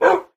Minecraft / mob / wolf / bark3.ogg
bark3.ogg